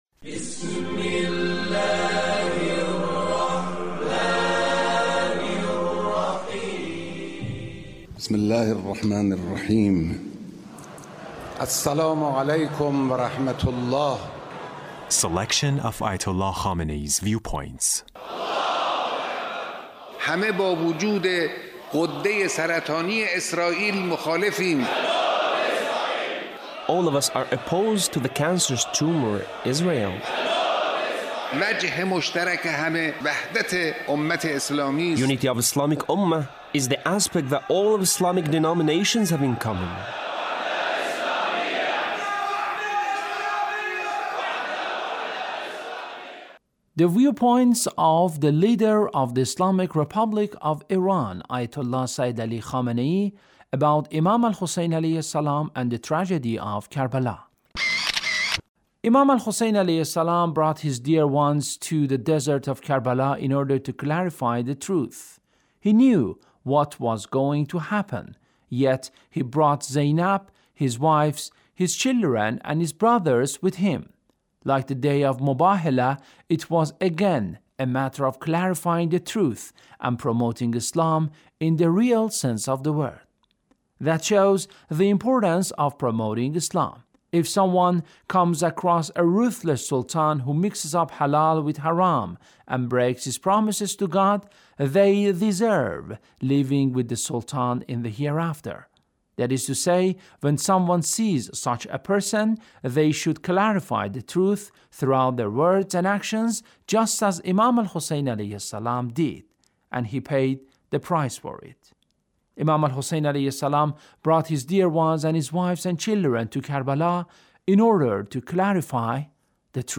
Leader's Speech (1774)